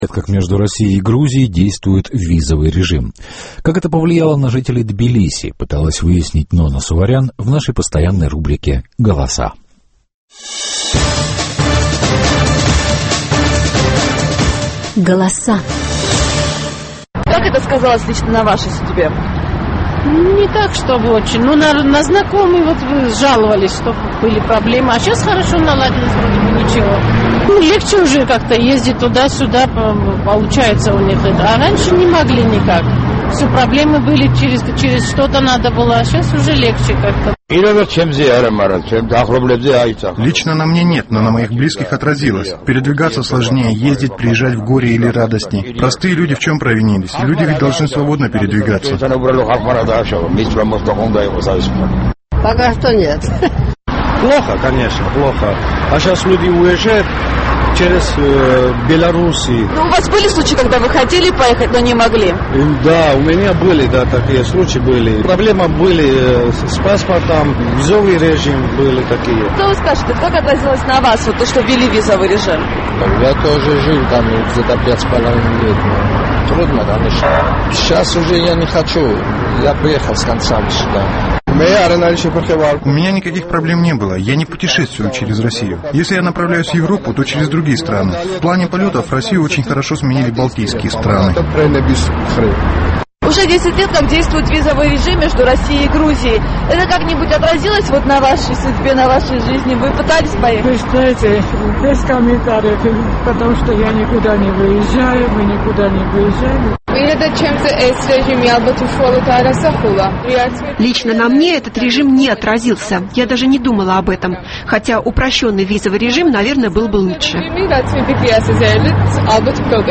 Голоса